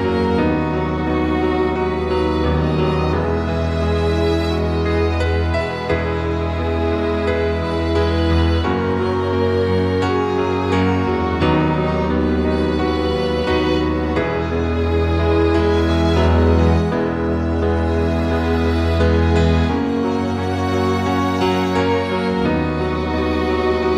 Up 4 Semitones For Male